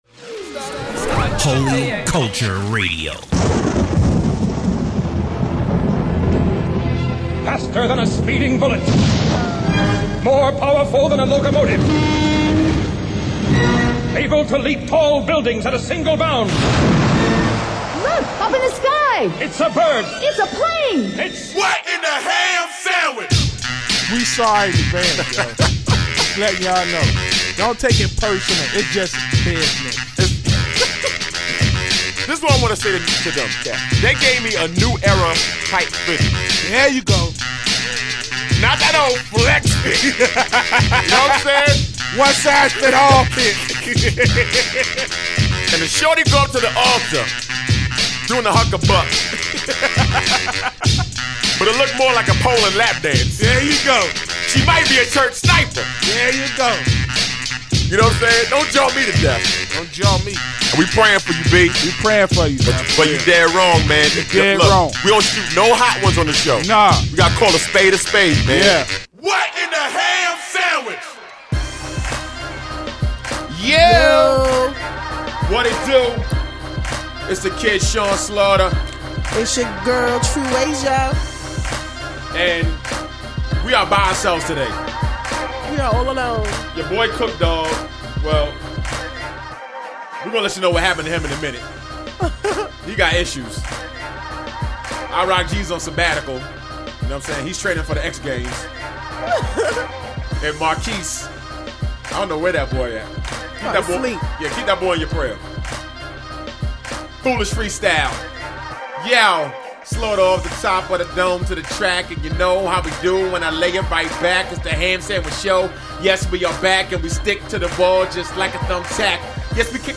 Christian Hip Hop
Christian Radio Show, Christian Rap